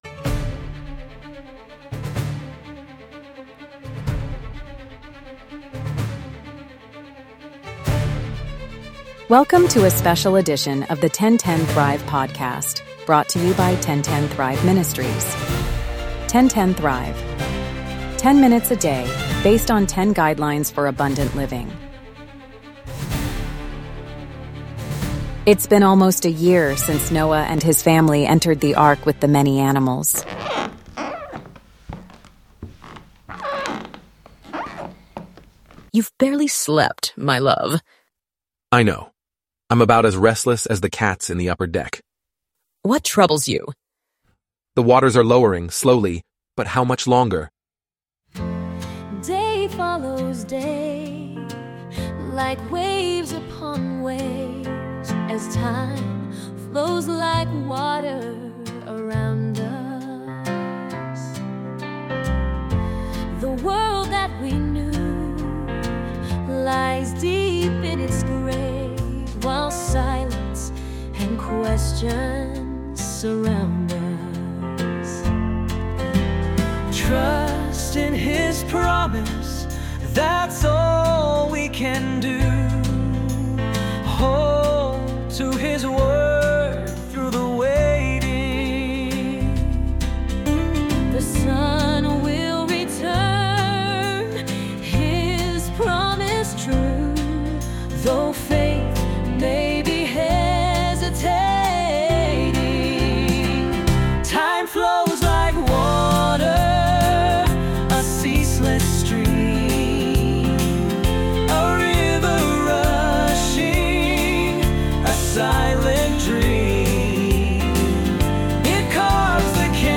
Today’s episode is Part 6 of “Making Waves,” a special musical theater edition of 1010 Thrive.